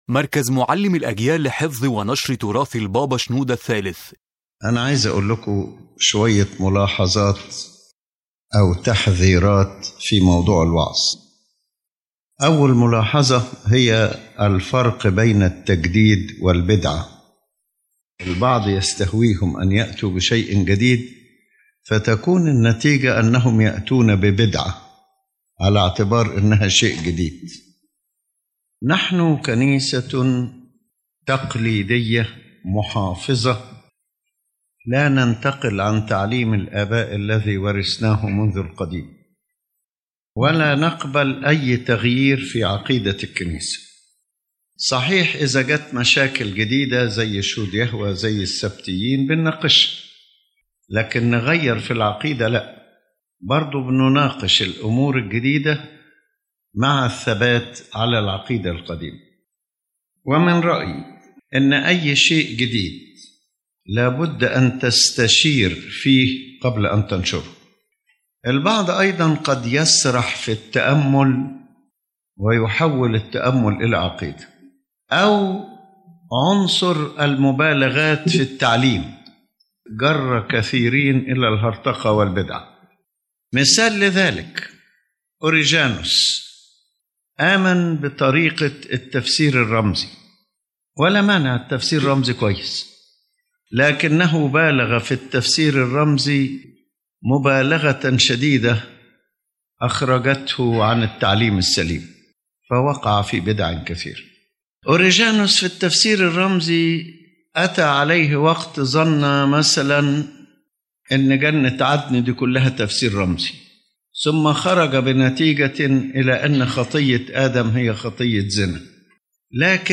تدور هذه المحاضرة حول مسؤولية الوعظ والتعليم في الكنيسة، وخطورة الانحراف عن الإيمان المستقيم تحت ستار التجديد أو الحرية الفكرية. يؤكد قداسة البابا شنوده الثالث أن الوعظ ليس مجالًا لعرض الآراء الشخصية أو استعراض الثقافة، بل هو أمانة لنقل تعليم الكنيسة الأرثوذكسية كما تسلمته من الآباء، دون زيادة أو نقصان.